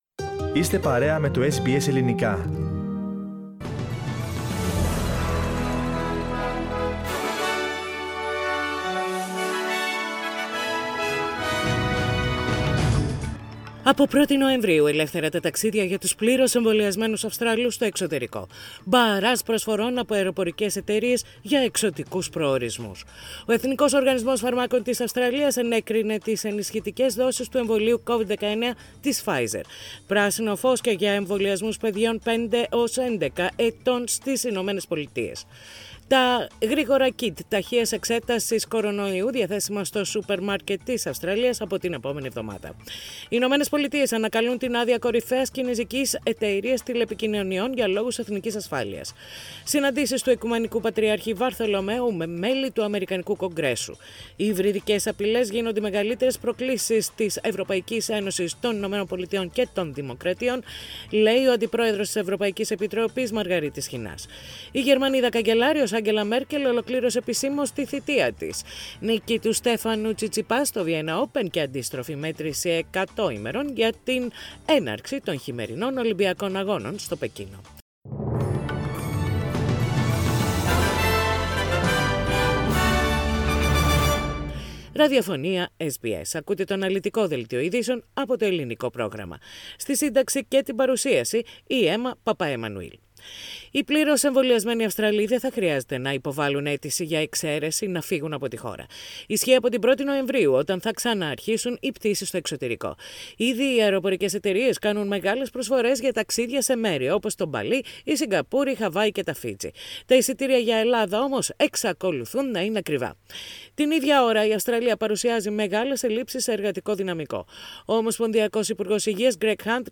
Ειδήσεις στα Ελληνικά - Τετάρτη 27.10.21